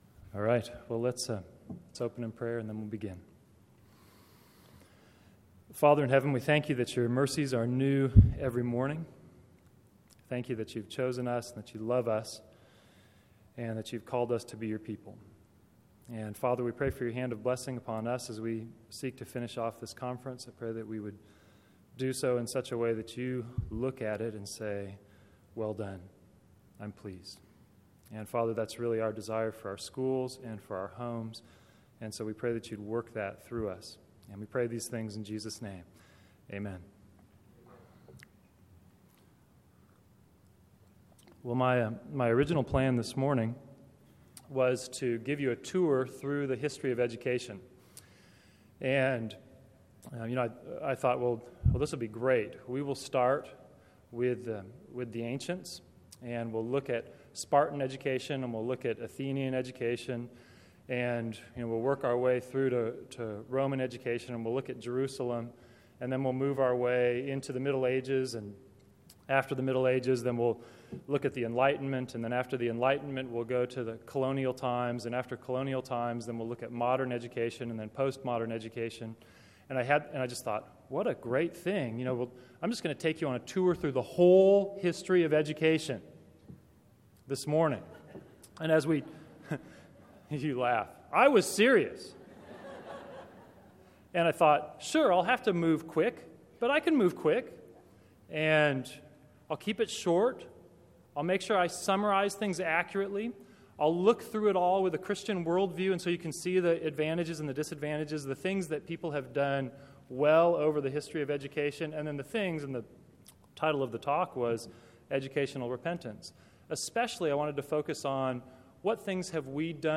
2012 Plenary Talk | 1:07:43 | All Grade Levels, Virtue, Character, Discipline